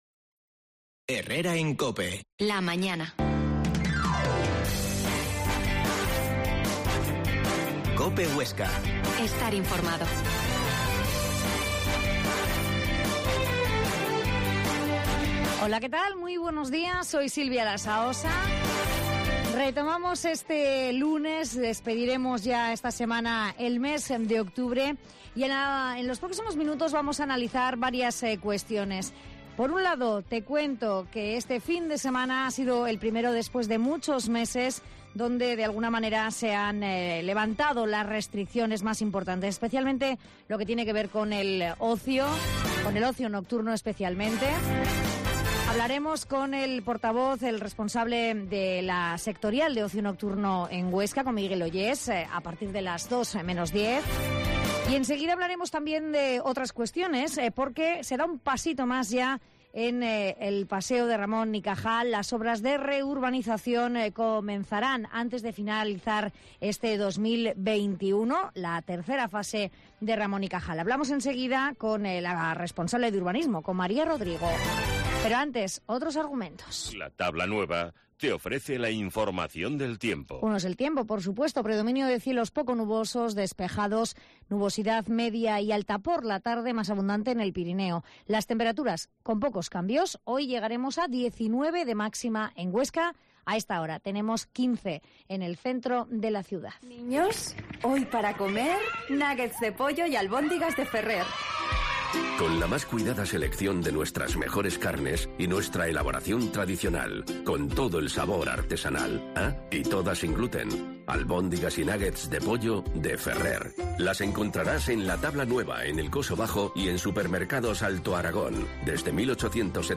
Herrera en Cope Huesca 12,50h. Entrevista a la responsable de Urbanismo en Huesca